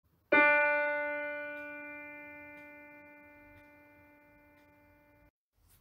Звуки пианино
Звук ноты Ре на пианино